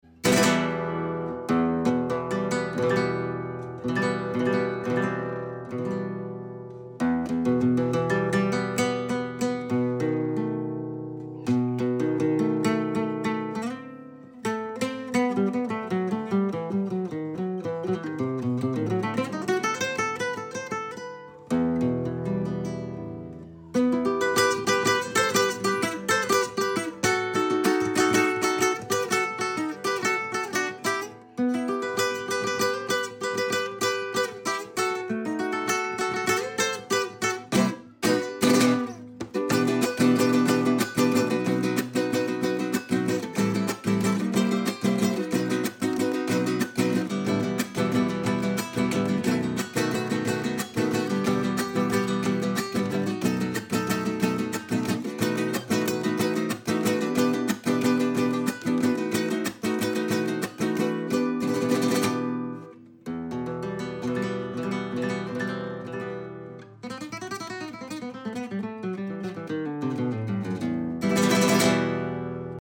mariachi
guitar